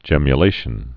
(jĕmyə-lāshən)